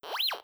metal_detector.wav